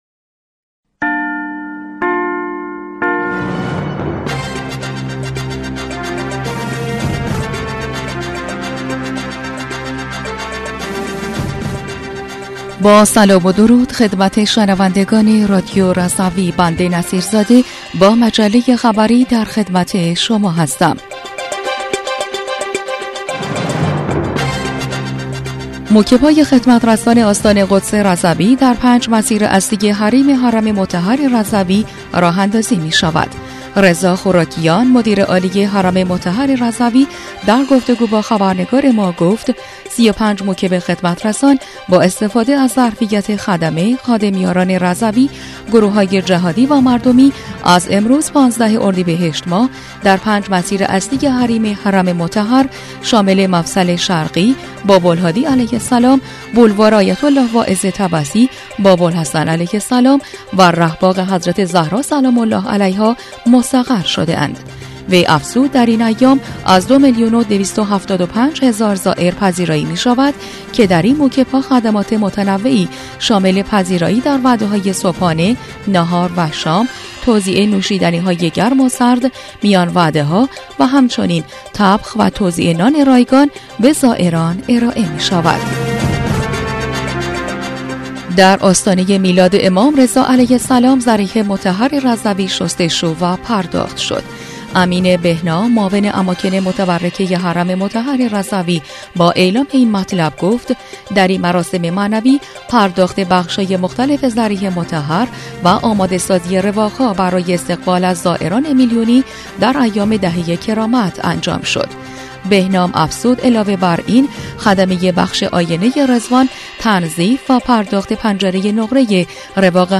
بسته خبری 15 اردیبهشت رادیو رضوی؛